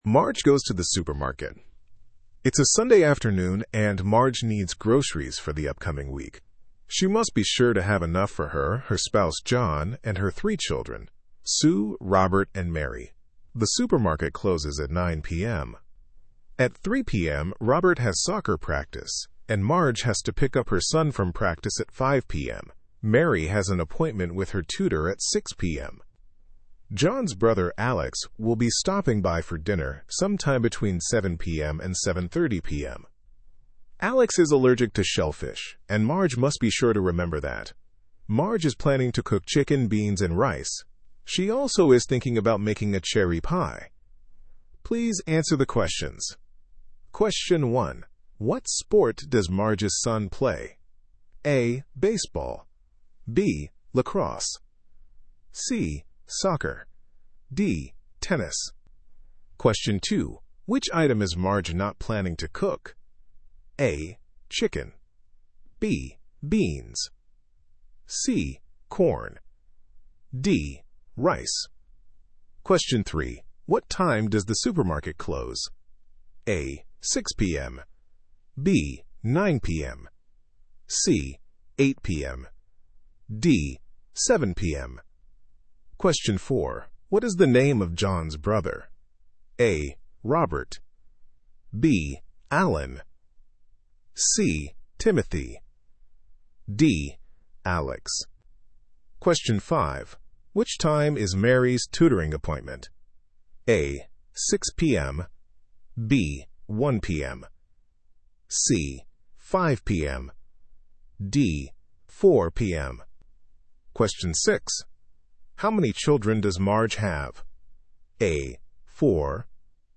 Estados Unidos